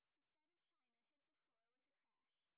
sp20_white_snr20.wav